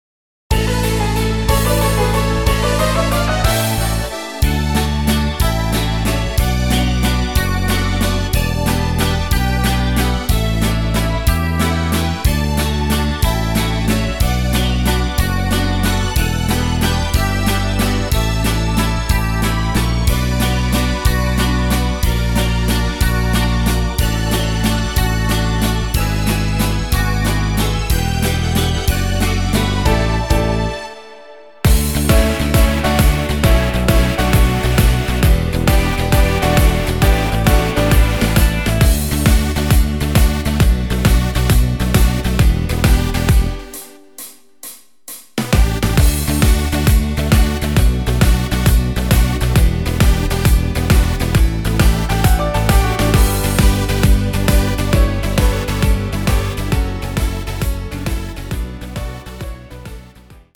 Ein neuer Kölsche Hit